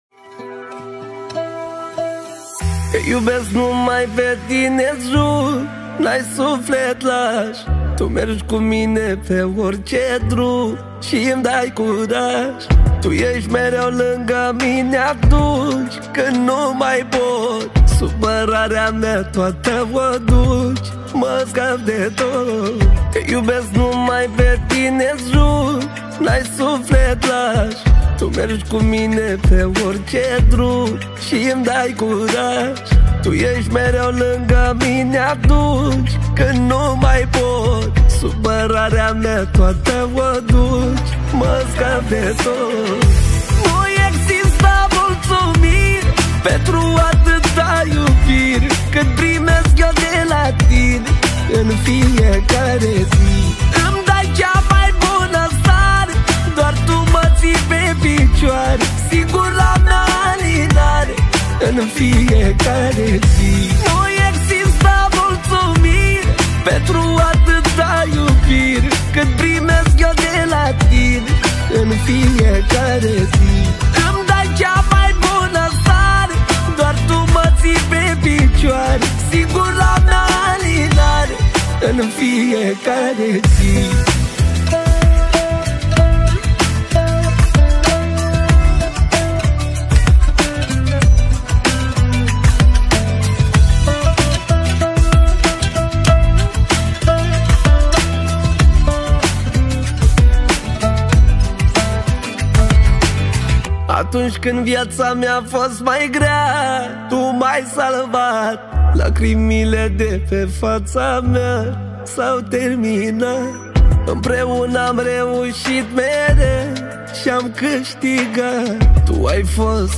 Data: 10.10.2024  Manele New-Live Hits: 0